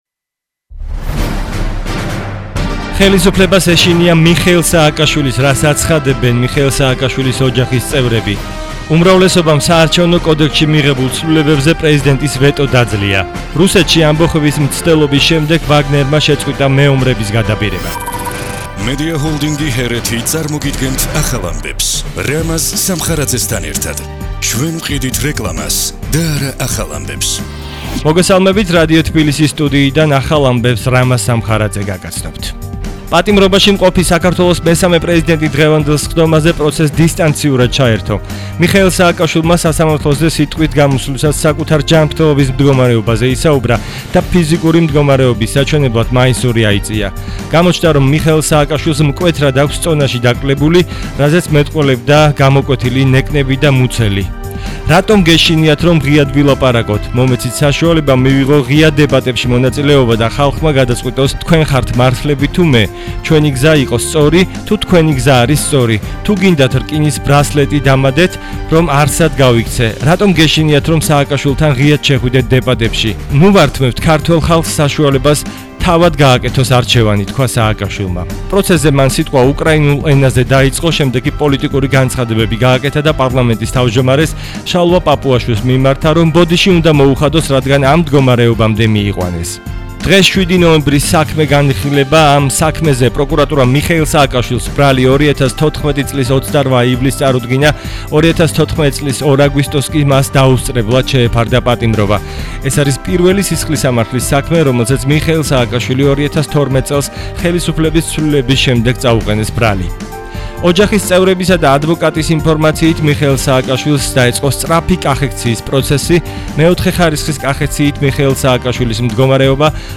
საღამოს ახალი ამბები – 19:00 საათზე - HeretiFM